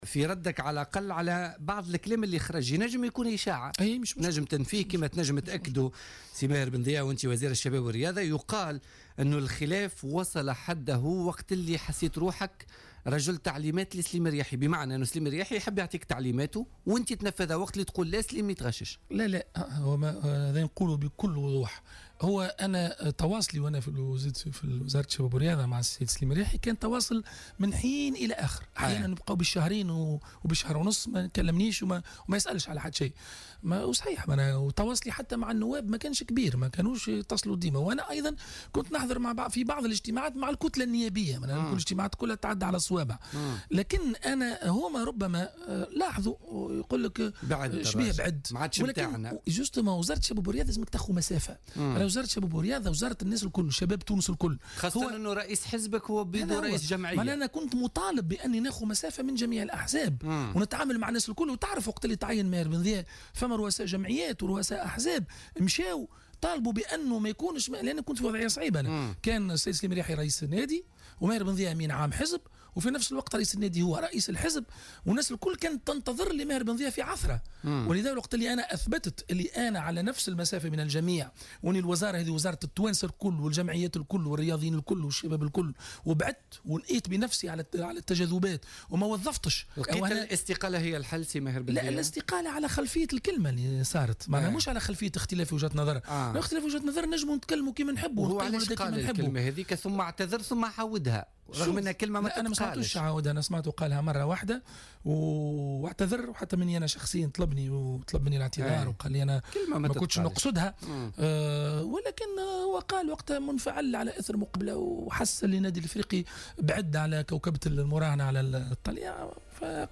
وقال لـ "الجوهرة أف أم" في برنامج "بوليتيكا"، إن تعيينه على رأس وزارة الرياضة يقتضي أن يكون خارج أي حزب، بحسب تعبيره، مؤكدا حرصه على الوقوف على نفس المسافة مع جميع الأطراف بحكم مسؤوليته.